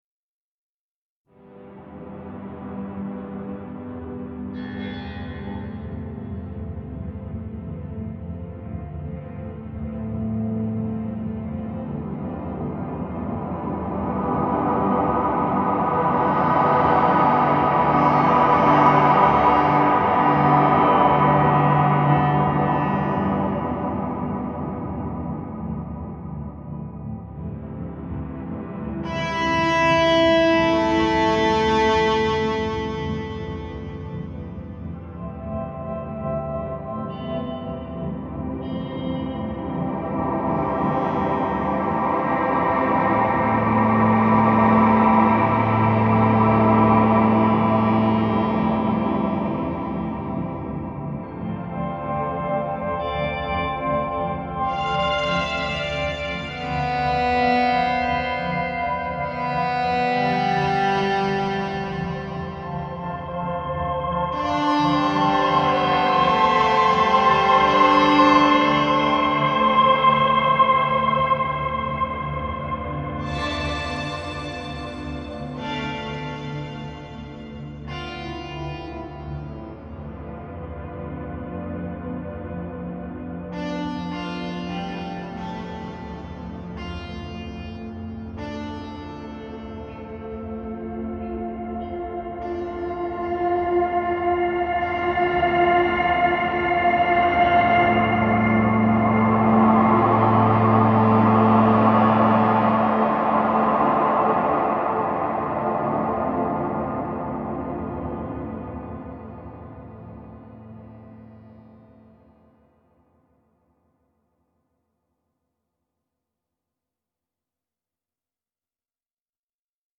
Einmal ein Gitarren Intro und einmal athmosphärische Klänge.